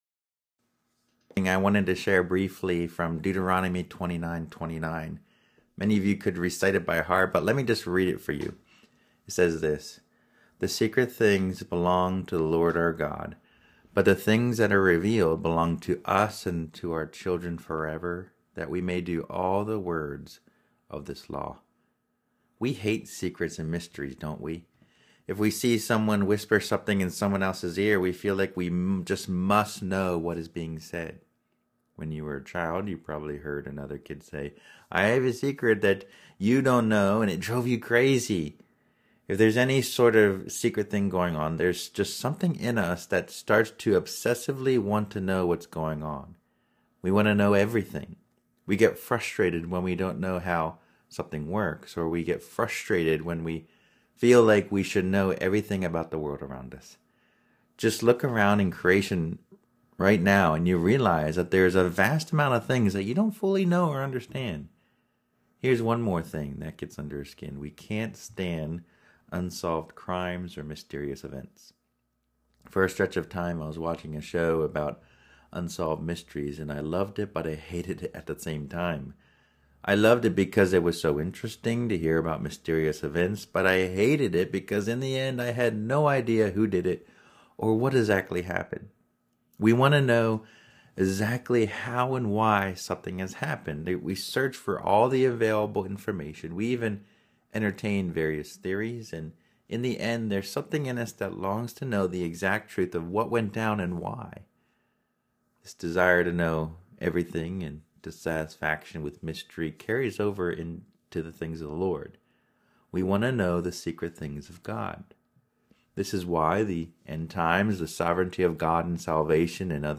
(This sermon was pre-recorded, as it was preached at our church picnic.)